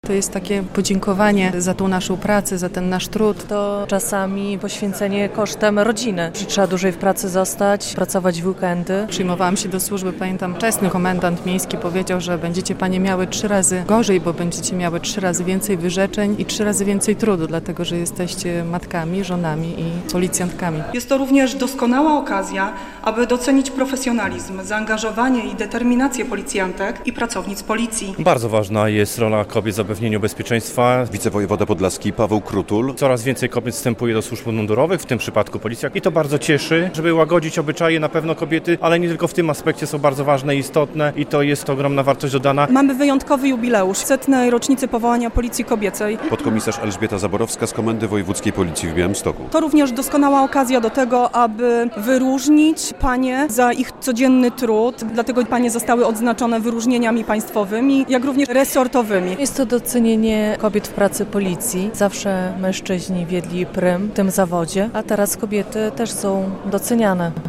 Wydarzenie odbyło się w Auli Magna Pałacu Branickich.
relacja
Wyróżnione panie mówią, że to służba ciekawa, ale też trudna i wymagająca poświęceń.
Komendant Wojewódzki Policji w Białymstoku nadinsp. Kamil Borkowski mówi, że kobiet stanowią ważną część podlaskiego garnizonu.